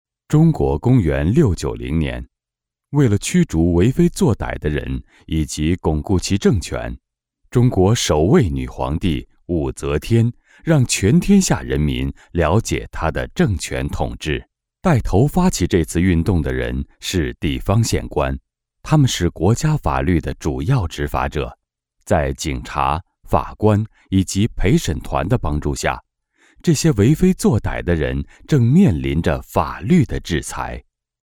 I have my own home studio which can provide qualified recording and fast turn arround.
Kein Dialekt
Sprechprobe: Werbung (Muttersprache):